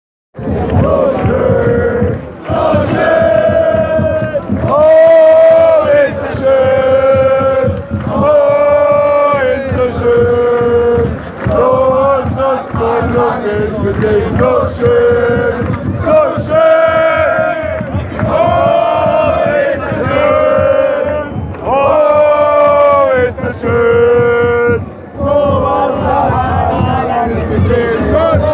Stadionstimmung im Sechzger zum anklicken